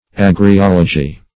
Search Result for " agriology" : The Collaborative International Dictionary of English v.0.48: Agriology \Ag`ri*ol"o*gy\ ([a^]g`r[i^]*[o^]l"[-o]*j[y^]), n. [Gr.